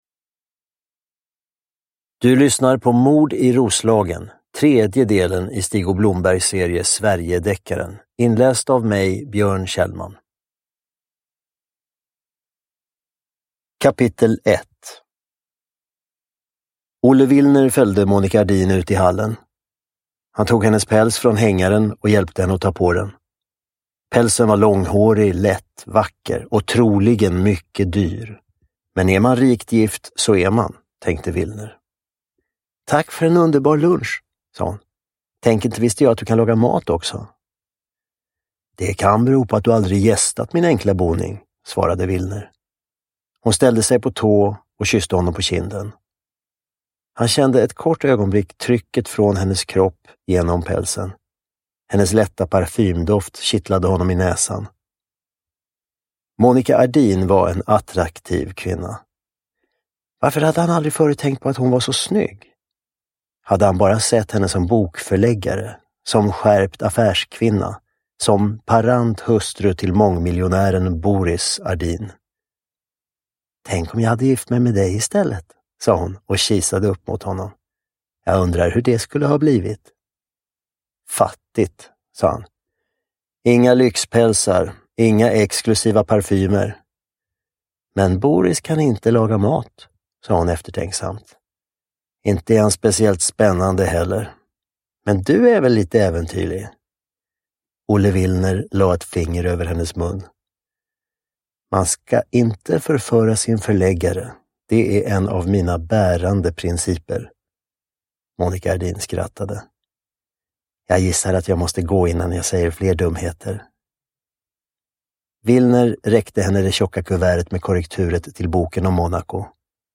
Uppläsare: Björn Kjellman